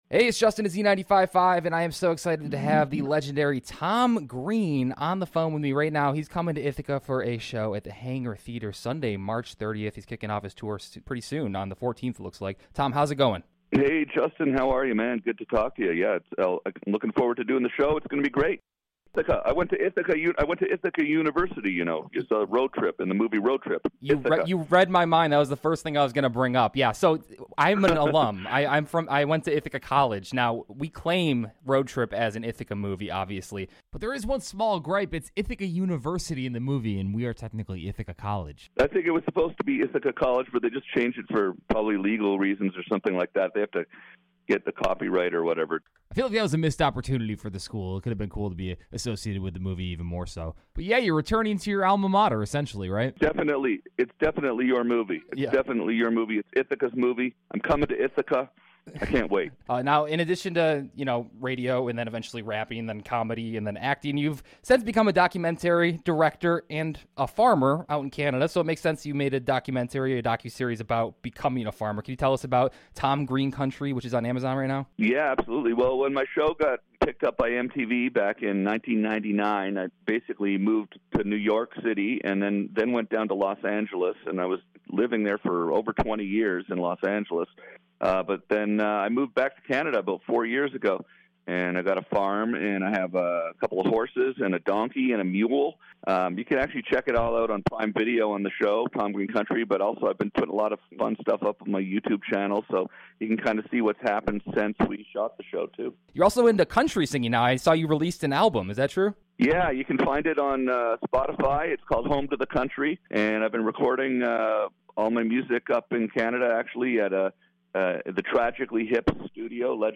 Tom-Green-Interview-EDIT.mp3